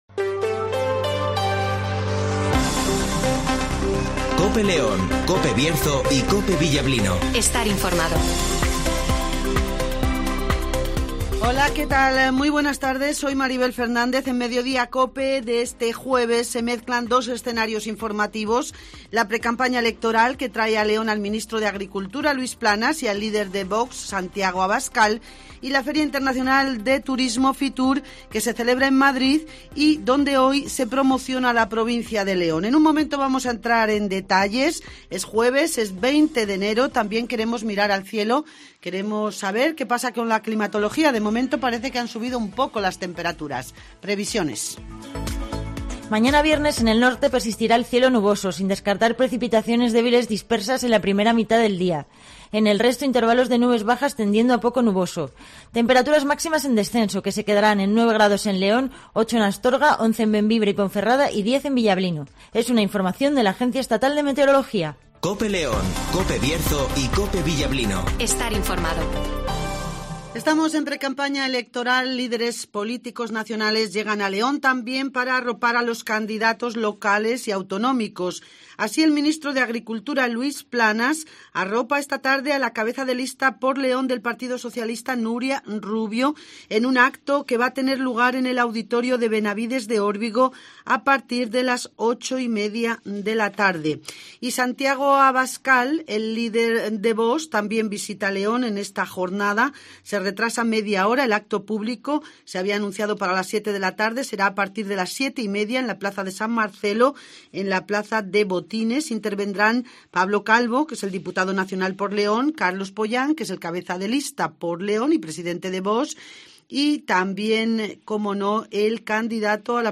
- José Antonio Díez ( Alcalde de León )
- Antonio Silván ( Senador )